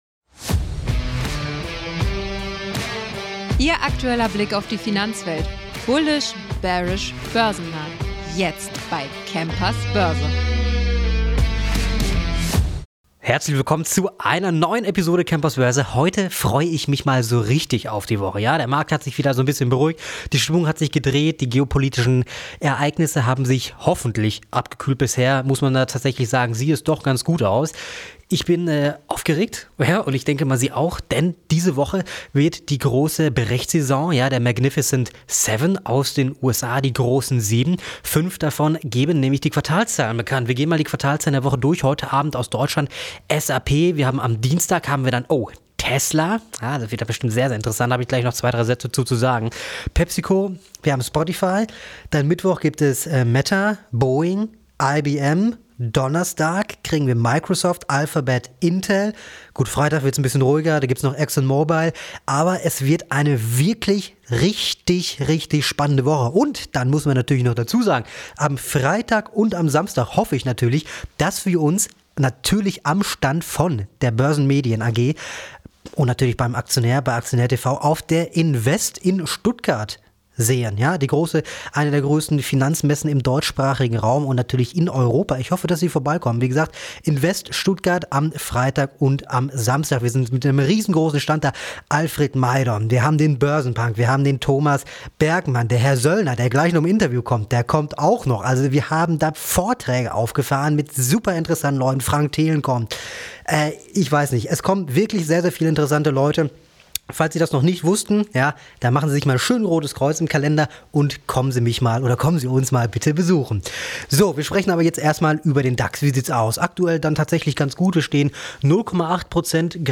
Im Experten-Talk